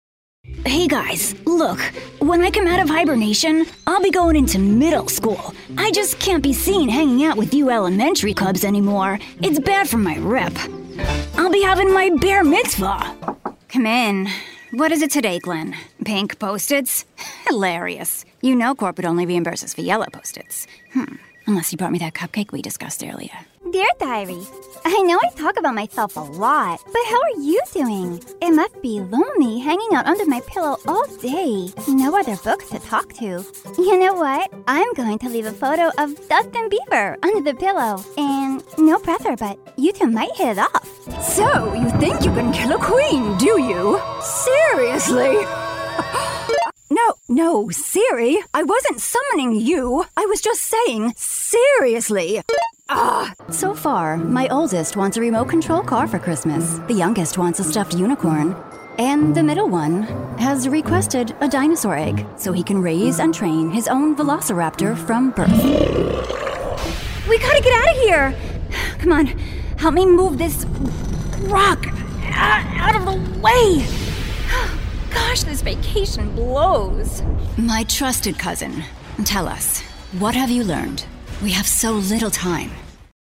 Female
Bright, Bubbly, Friendly, Warm, Confident, Natural, Young, Approachable, Conversational, Energetic, Soft, Upbeat
Microphone: TLM 102, RE20
Audio equipment: Whisper Room Booth, Apollo Twin Interface, DBX 286s preamp/processor, Aventone speakers